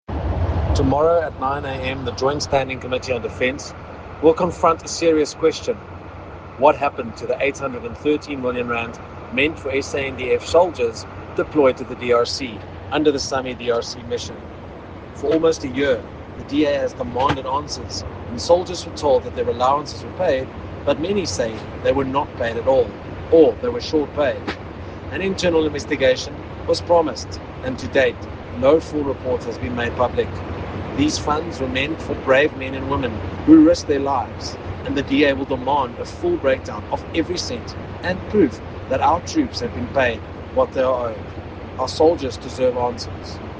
Attention Broadcasters: Attached is a voicenote by Nicholas Gotsell MP, DA Member on the Select Committee on Security & Justice in